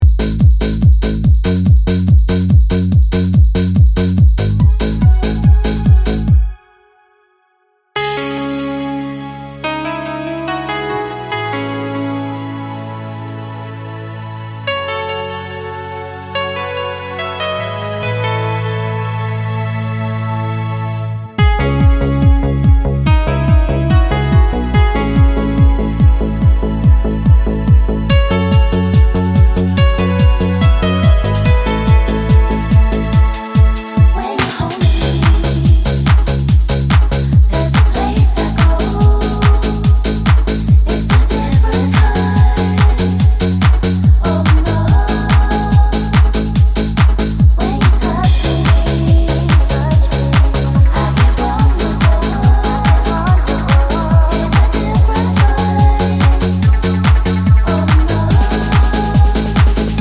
no Backing Vocals Dance 3:05 Buy £1.50